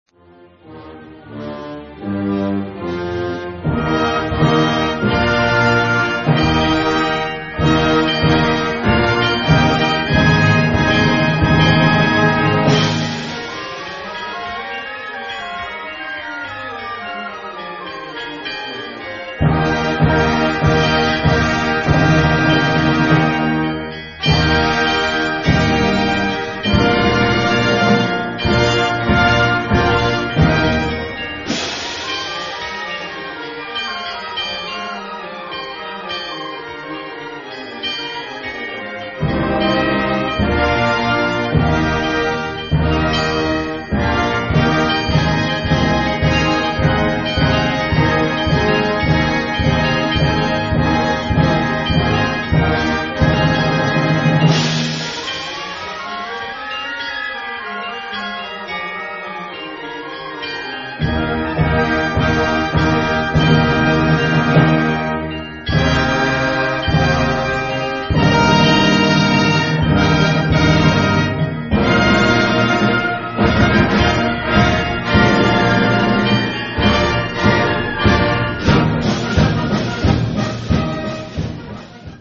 実際の音色
実際の演奏で使った時の音源です。
説　明 ： 一番最後に出てくる教会の鐘の音で使いました。
本物の鐘の音に近いのでチャイムより効果的です。